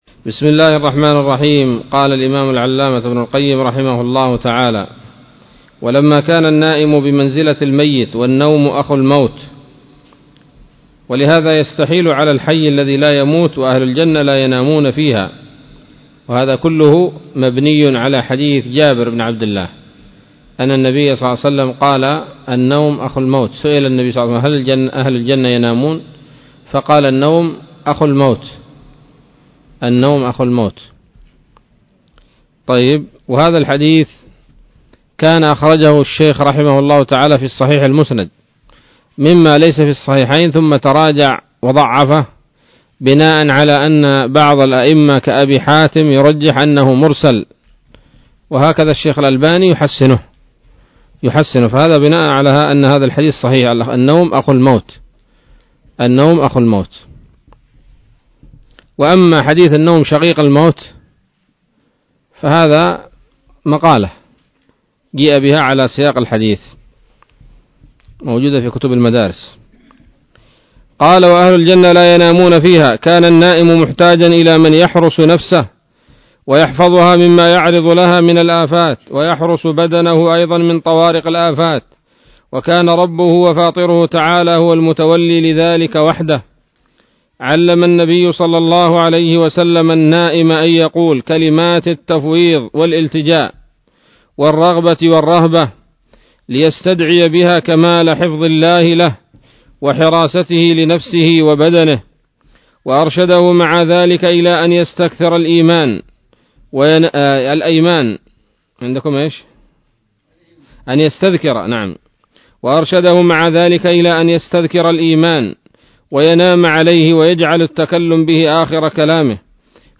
الدرس السابع والستون من كتاب الطب النبوي لابن القيم